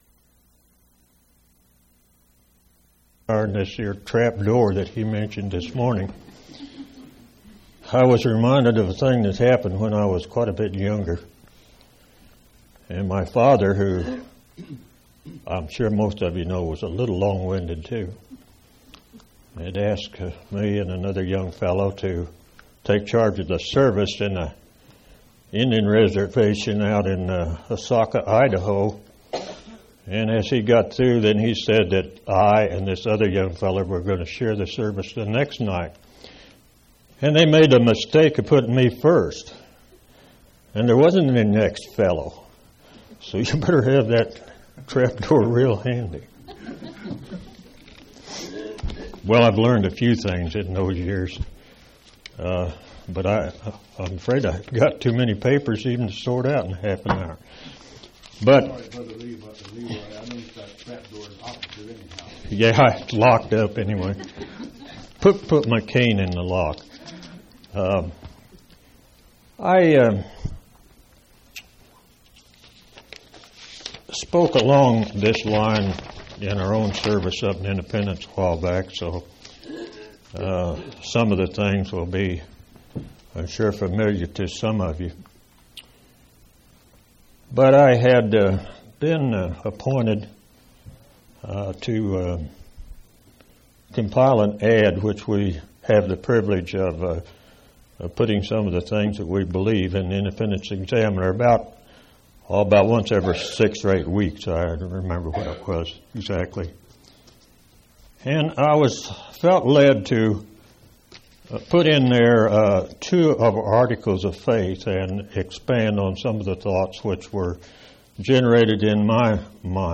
10/16/1988 Location: Collins Rally Event